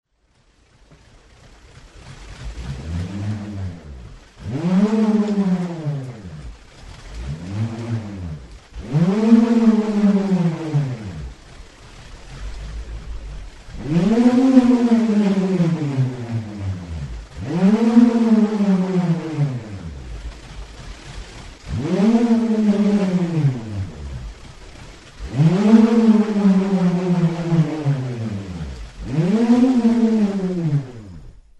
Aerophones -> Free-vibrating
Recorded with this music instrument.